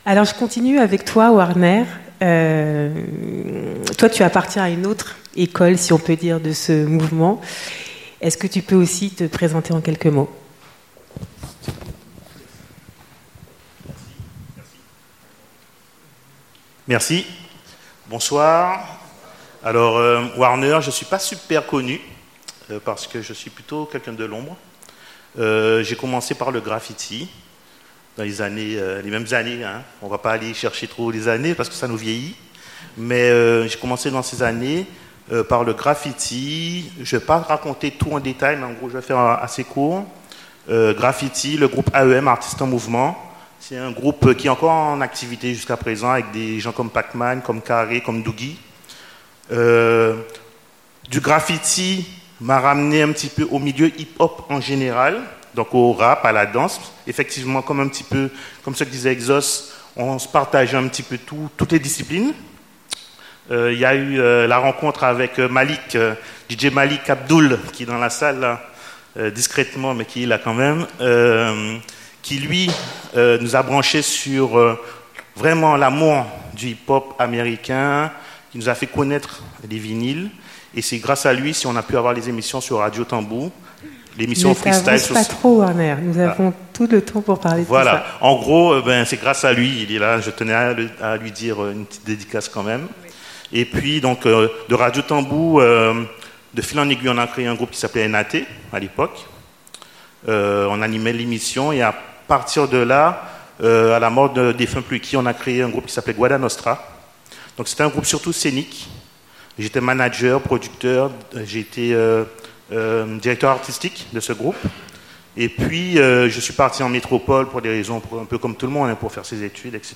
Atelier musique
au Centre Culturel Gérard Lockel (Belcourt, Baie-Mahault). Durée : rencontre (2h 8 mn), questions (37 mn)